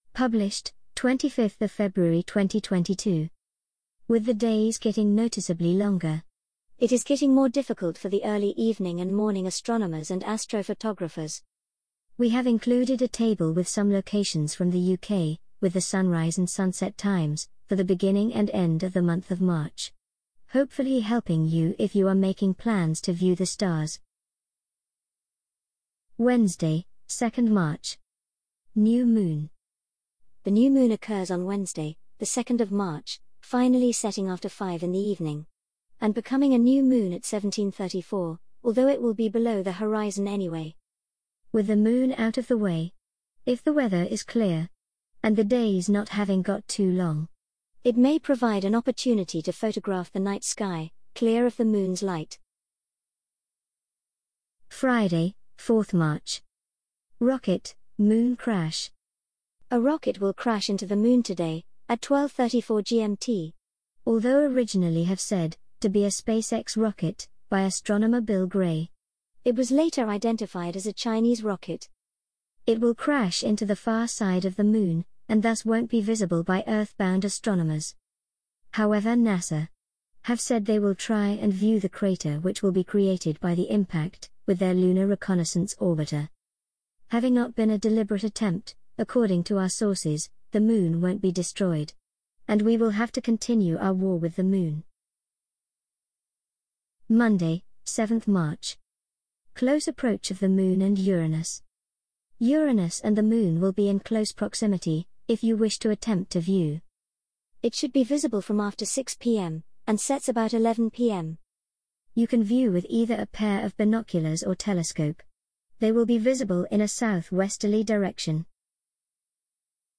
An audio reading of the Realm of Darkness March 2022 Article